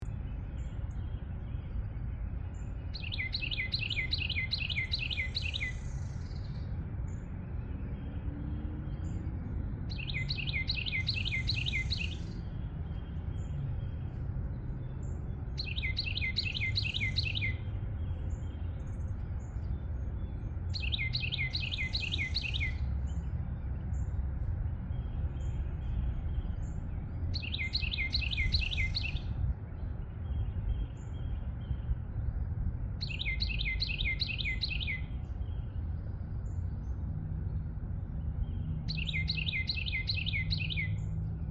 Sound Effects
Birds 1